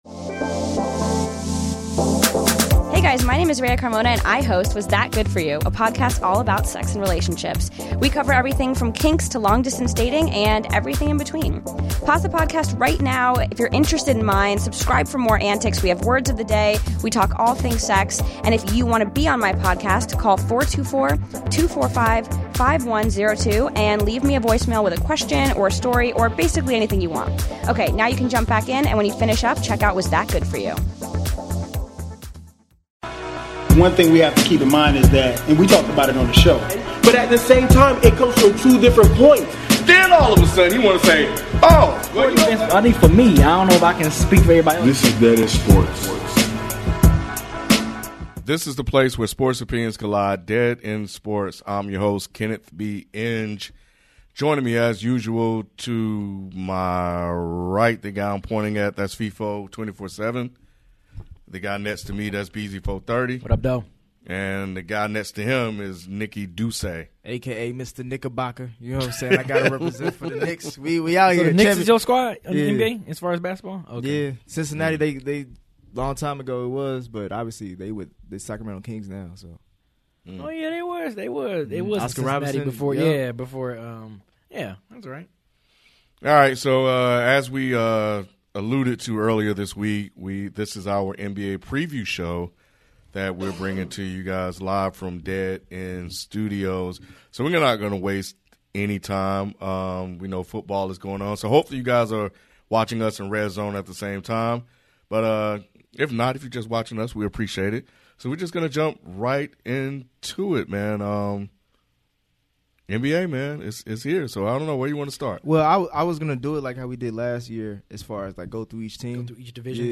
Our favorite part of recording a live podcast each week is participating in the great conversations that happen on our live chat, on social media, and in our comments section.